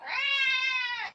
cat4.mp3